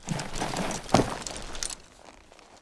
mount.ogg